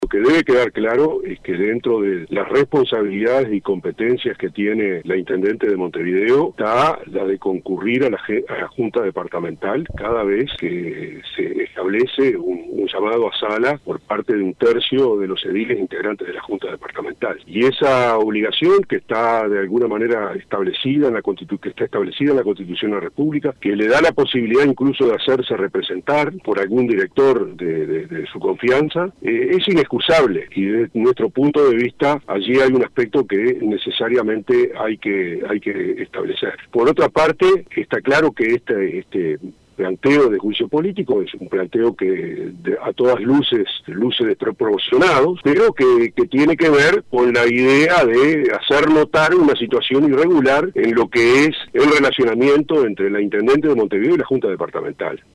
El diputado del Partido Independiente, Iván Posada, habló con 970 Noticias respecto al juicio político impulsado por los ediles de la coalición a la intendenta de Montevideo, Carolina Cosse, tras su ausencia en la Junta Departamental.